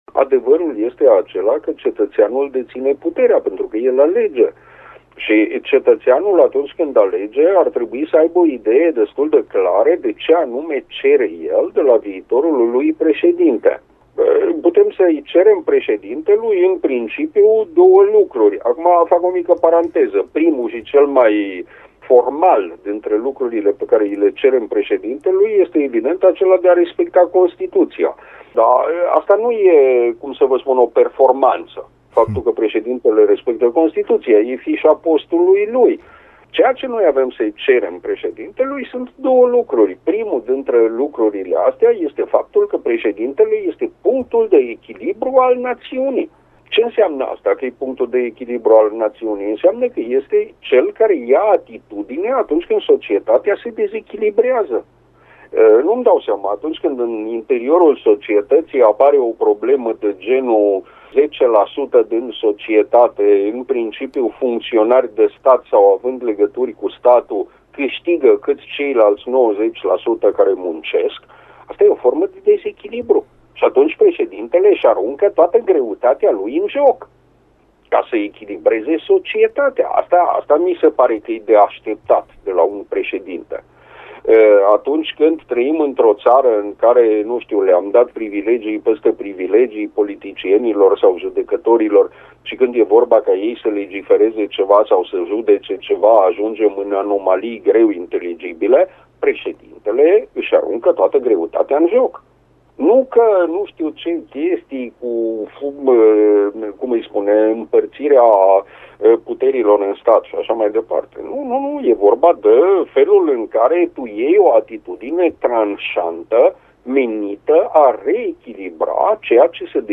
invitat la Serile Radio Cluj.